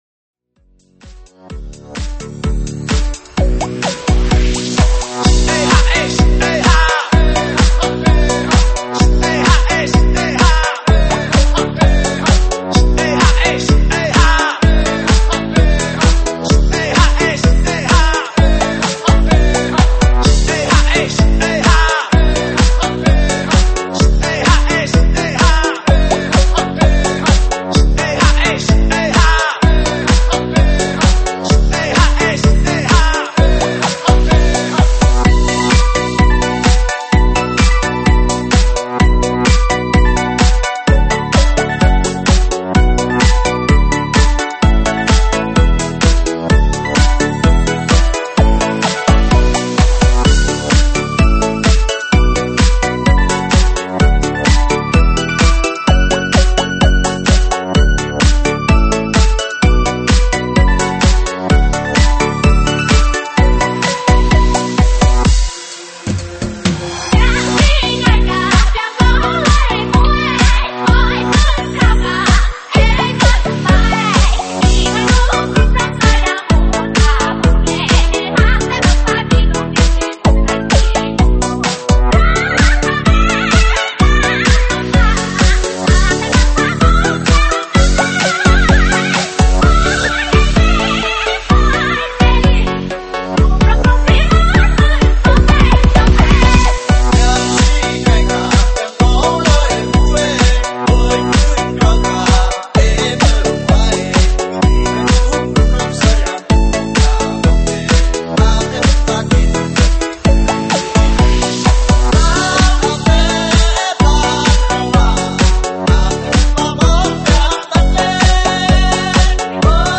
(现场串烧)